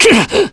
Clause-Vox_Damage_kr_02.wav